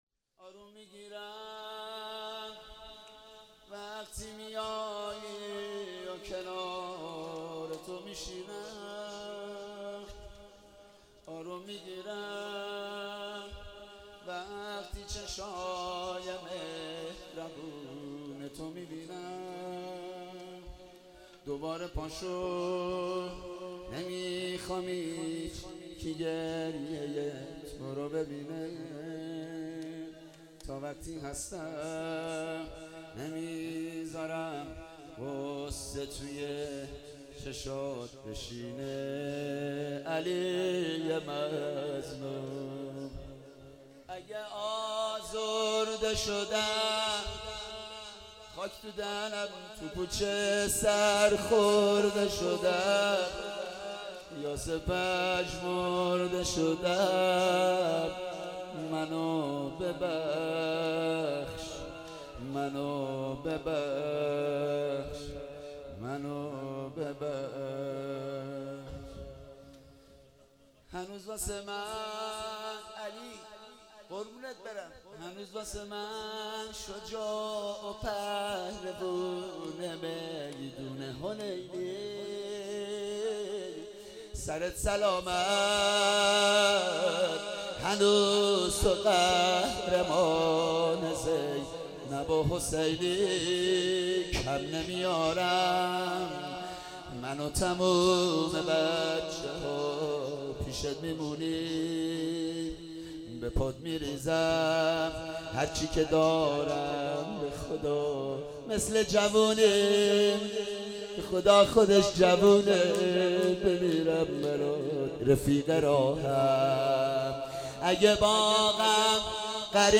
ایام فاطمیه 95
زمینه - آروم میگیرم وقتی میای